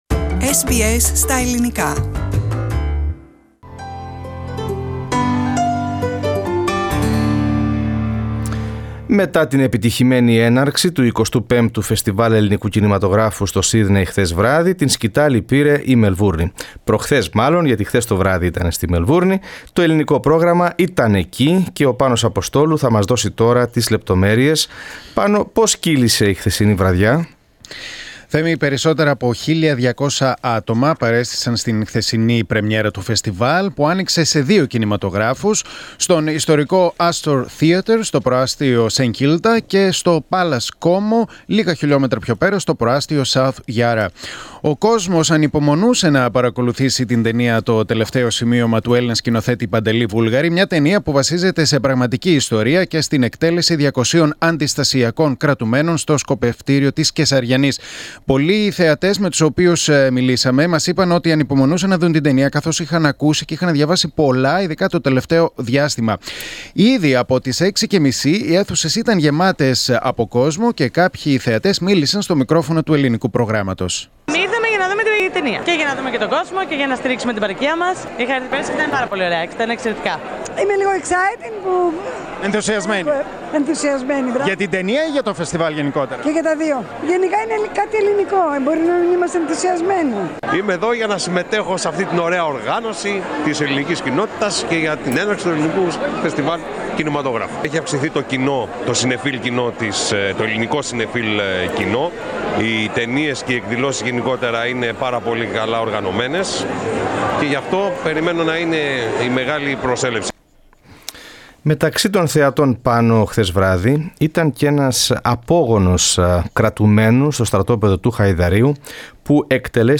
From the 25th Greek Film Festival Opening Night in Melbourne (2018) Source: SBS Greek